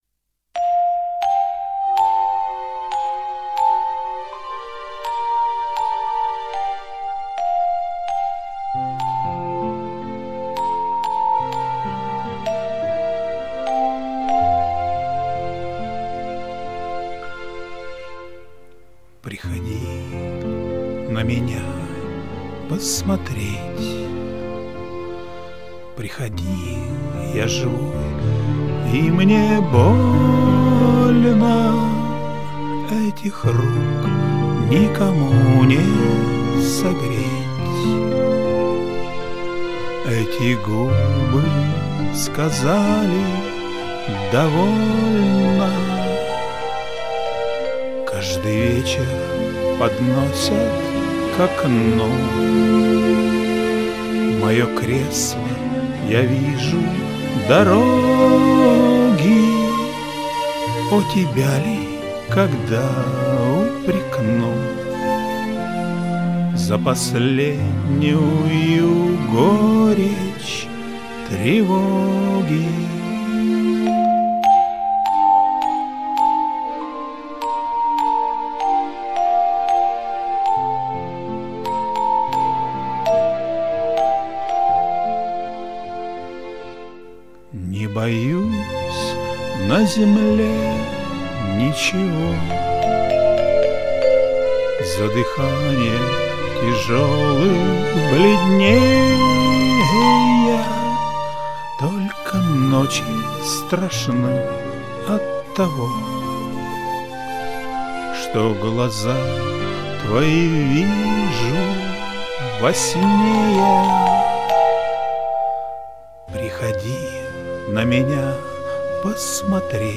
романс прозвучал мелодекламативно